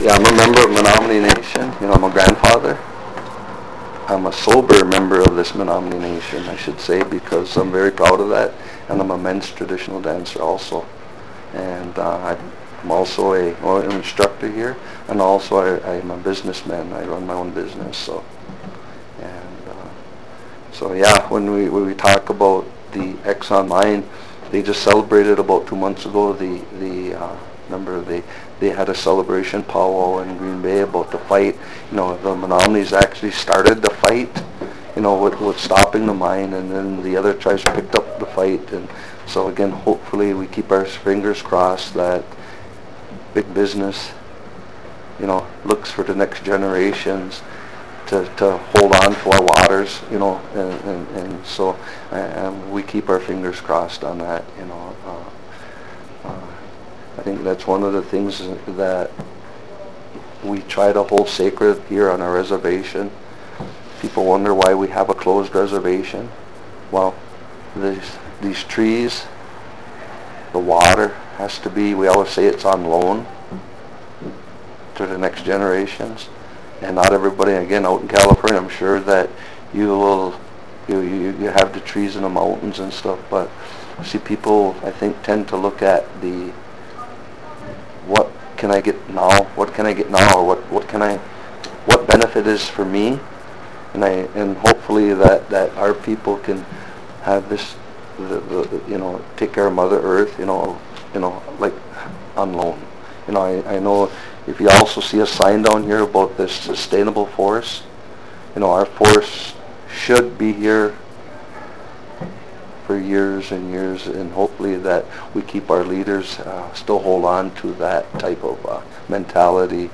• Menominee worker supports the fight that prevented Exxon pollution of river (1 meg .wav interview)